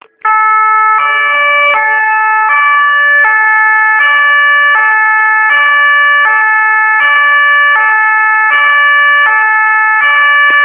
Feuerwehralarm (Sound für Handy)
fw_alarm.amr